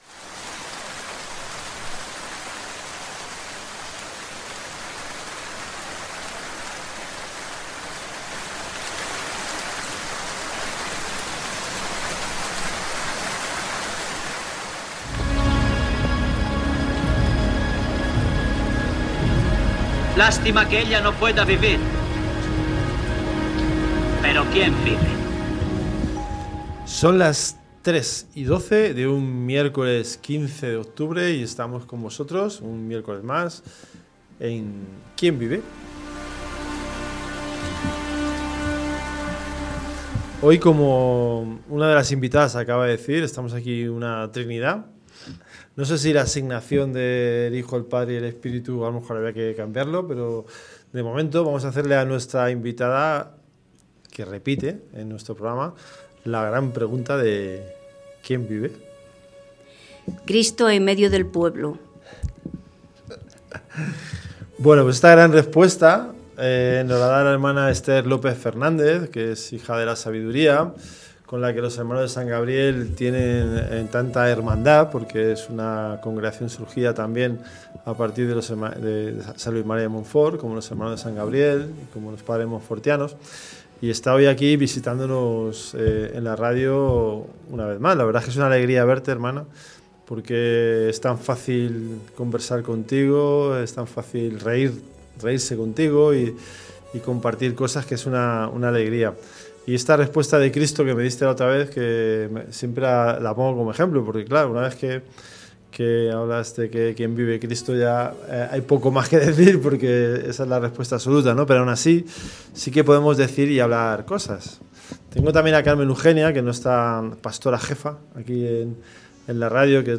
en una conversación especialmente cercana y entrañable.